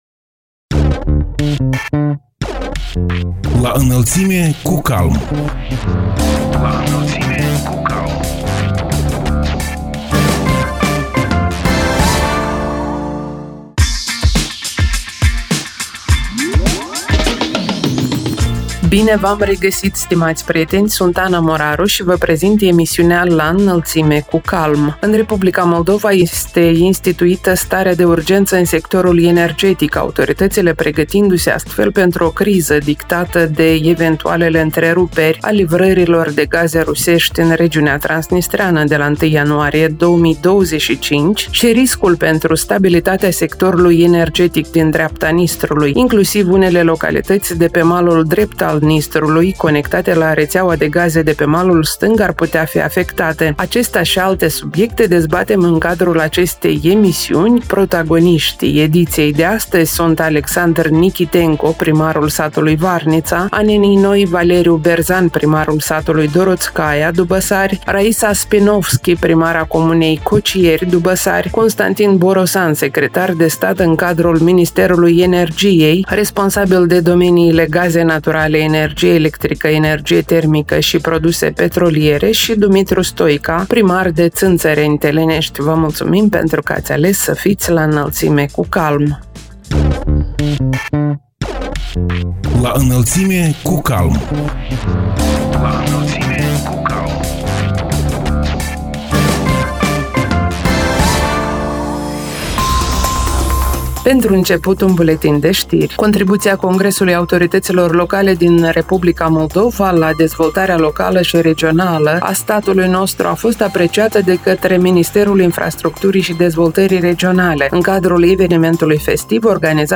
Protagoniștii ediției sunt: Alexandr Nichitenco, primarul satului Varnița, Anenii Noi; Valeriu Berzan, primarul satului Doroțcaia, Dubăsari; Raisa Spinovschi, primara comunei Cocieri, Dubăsari; Constantin Borosan, secretar de stat în cadrul Ministerului Energiei, responsabil de domeniile gaze naturale, energie electrică, energie termică și produse petroliere și Dumitru Stoica, primar de Țînțăreni, Telenești.